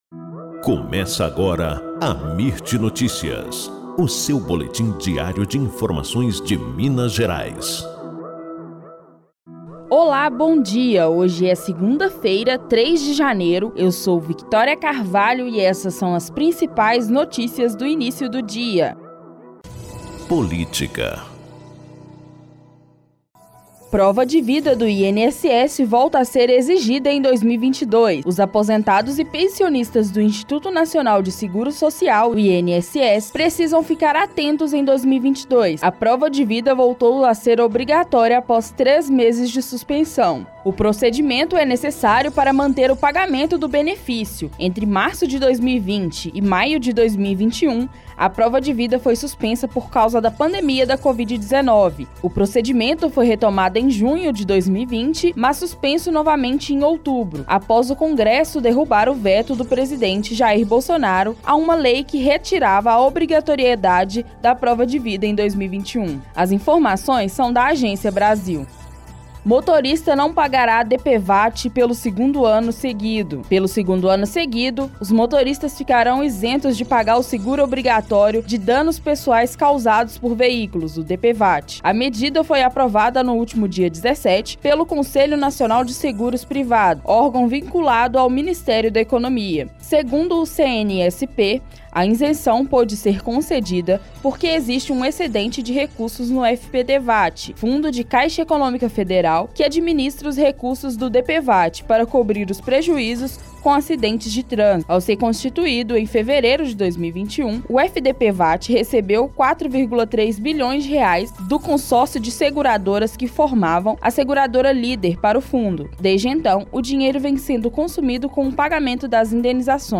Boletim Amirt Notícias – 03 de janeiro